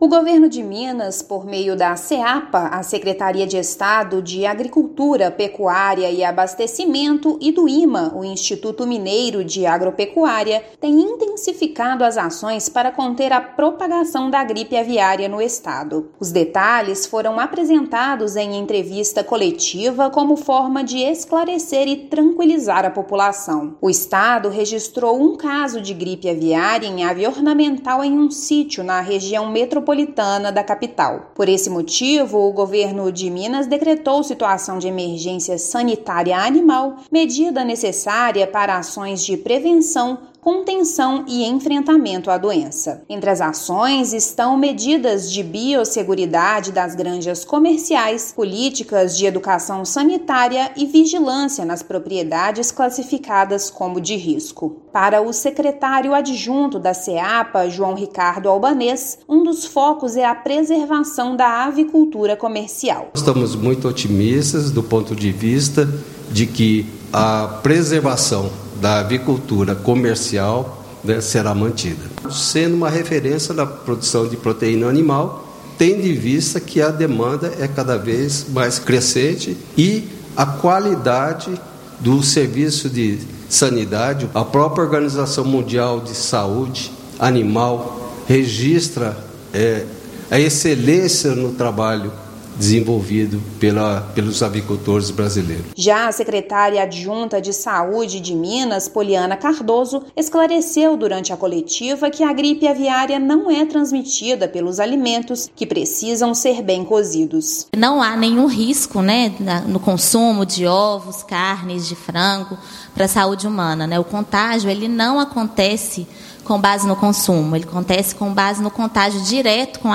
[RÁDIO] Governo de Minas reforça medidas para evitar disseminação da gripe aviária
Caso registrado em aves de vida livre não impacta a produção comercial; Saúde esclarece que é seguro consumir ovos e carne de frango. Ouça matéria de rádio.